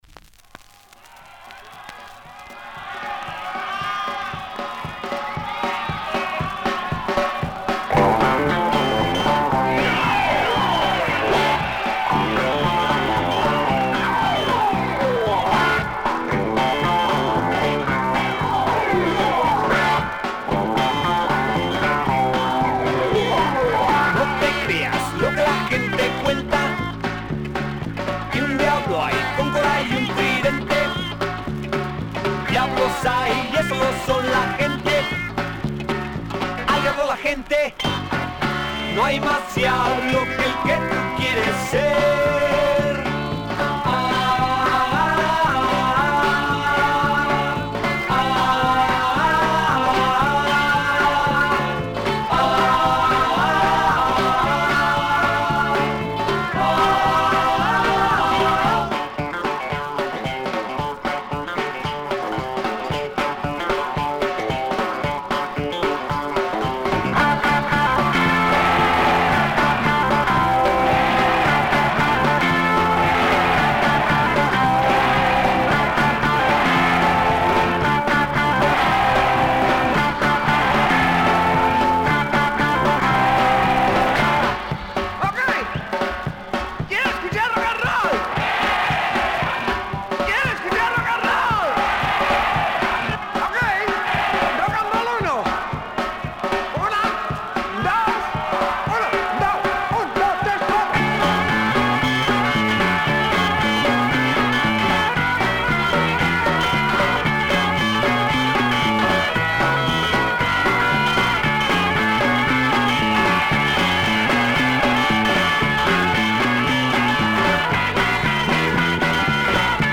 Published February 20, 2010 Garage/Rock Comments